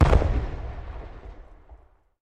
firework_distance_03.ogg